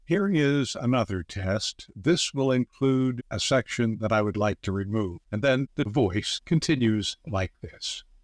I needed only to select the words I wanted to eliminate, the pauses I wanted to close up, and the error. Clicking the trash can eliminated them.
The edits aren’t perfect.